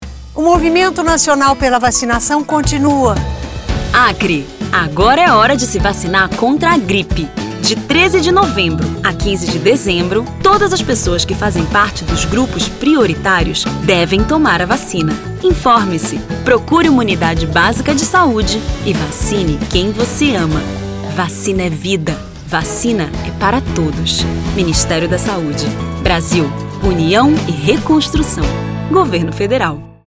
Acre: Spot - Vacinação Contra a Gripe no Acre - 30seg .mp3